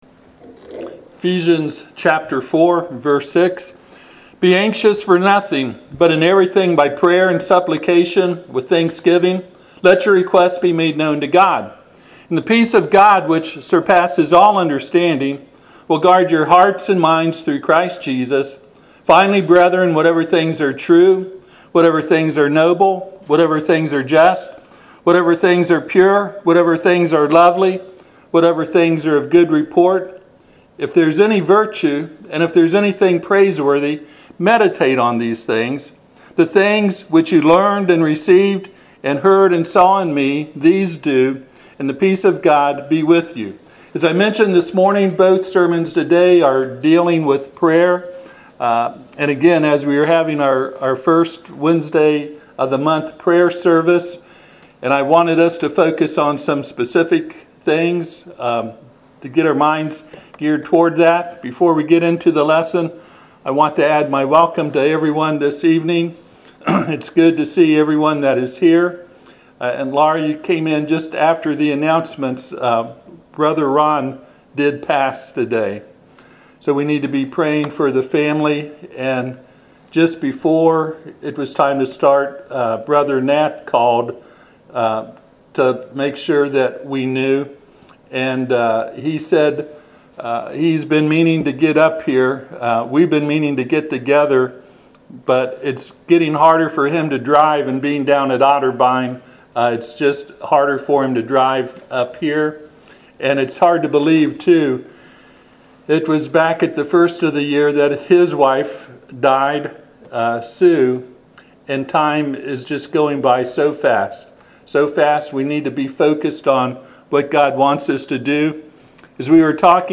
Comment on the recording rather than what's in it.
Service Type: Sunday PM